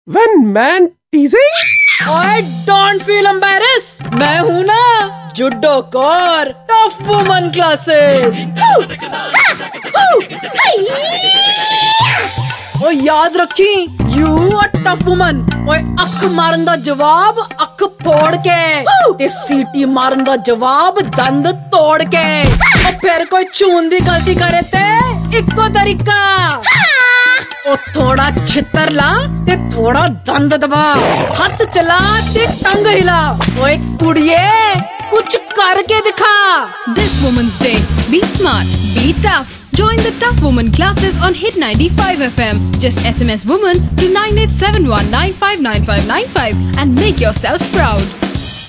Indian, Hindi, Punjabi, Neutal Accent, Friendly, Sexy, Conversational
Sprechprobe: eLearning (Muttersprache):